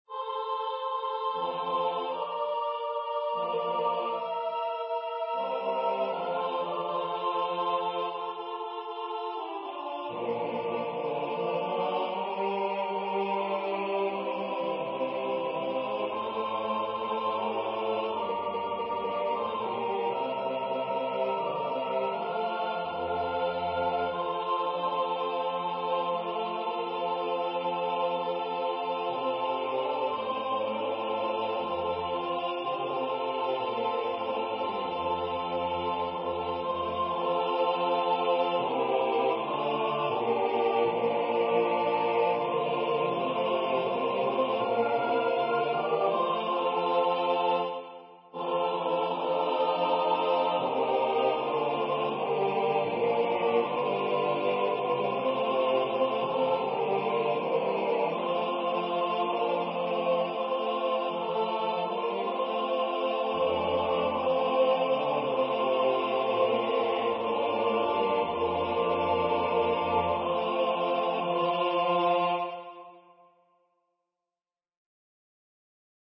The Sanctus movement begins with a cheer for God, then it slows down to a direct quote of "Deep River" in the Benedictus section.